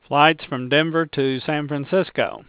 This speech signal is sampled at a rate of 8000 Hz.
phrase.au